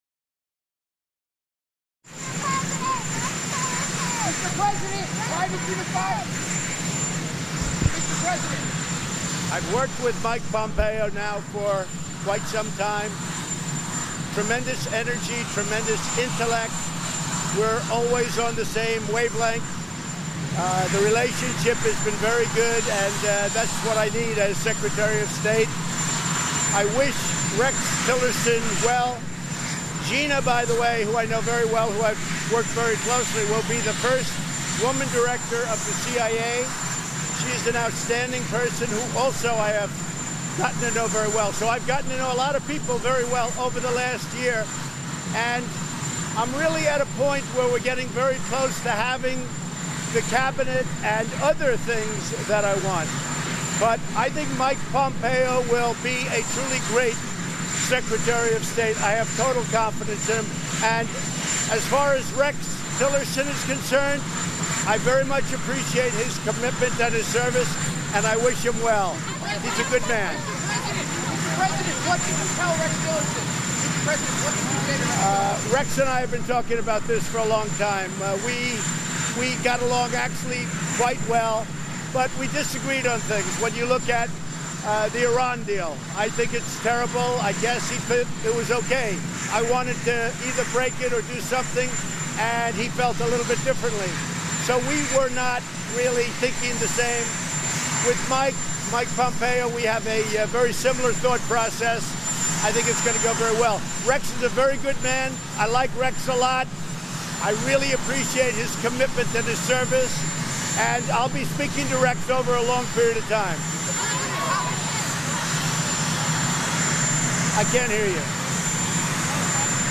U.S. President Donald Trump talks to reporters about his decision to fire Secretary of State Rex Tillerson